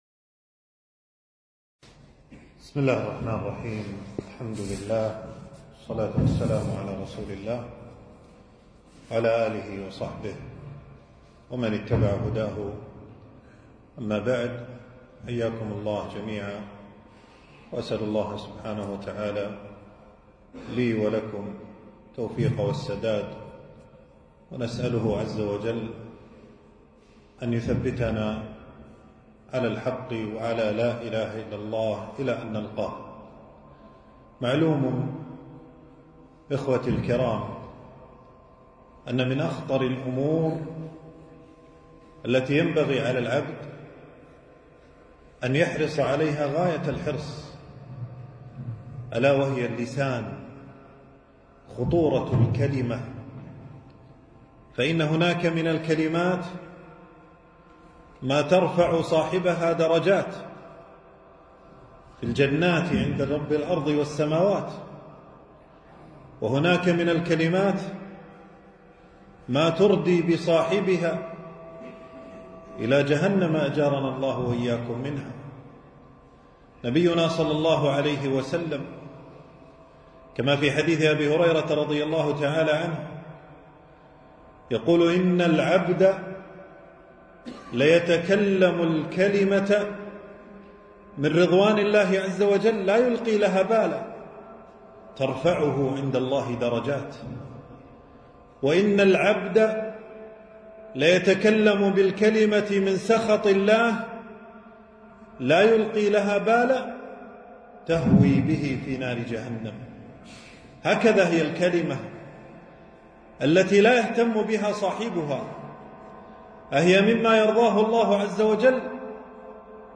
تنزيل تنزيل التفريغ محاضرة بعنوان: خطورة الغيبة والنميمة وآفات اللسان.
في مسجد أبي سلمة بالجهراء.